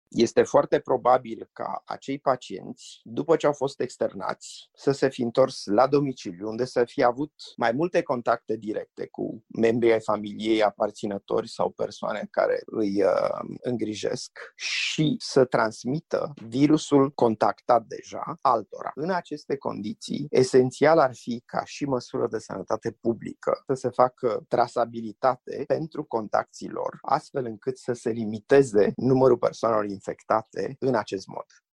medicul epidemiolog